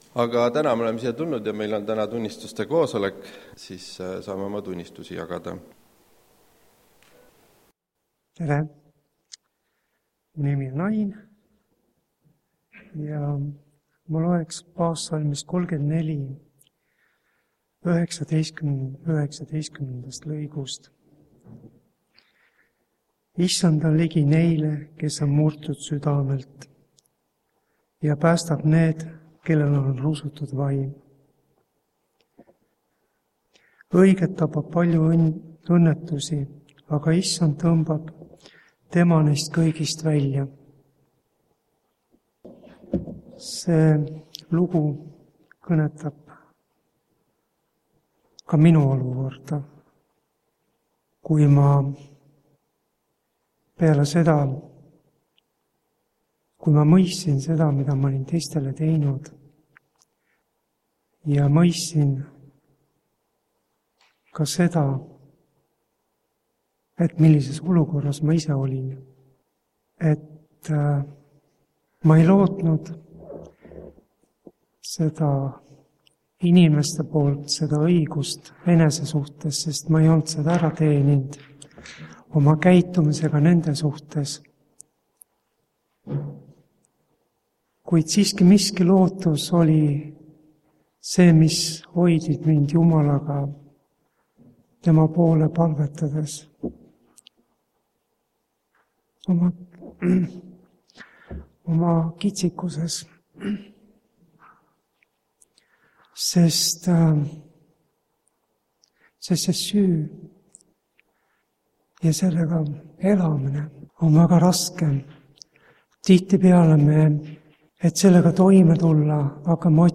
Täna on Haapsalus taas vaba mikrofon sest on